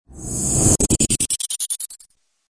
Descarga de Sonidos mp3 Gratis: zumbido 7.